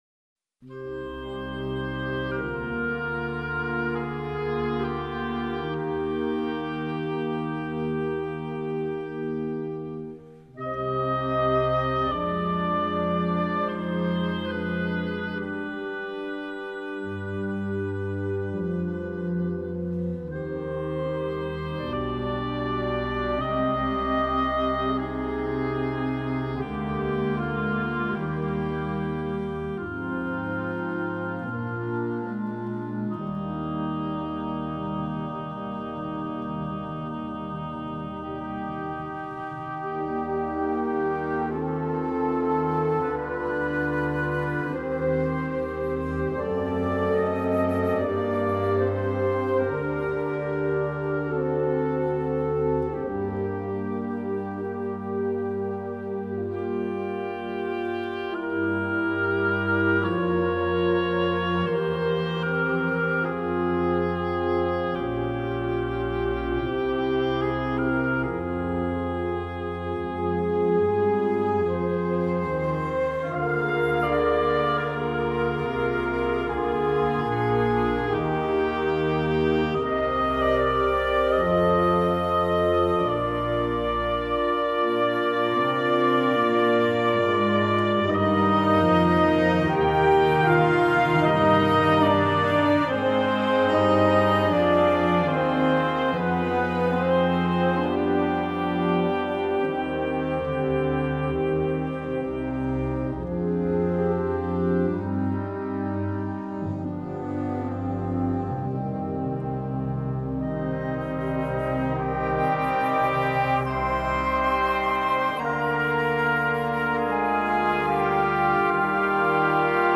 Gattung: Choral Prelude
Besetzung: Blasorchester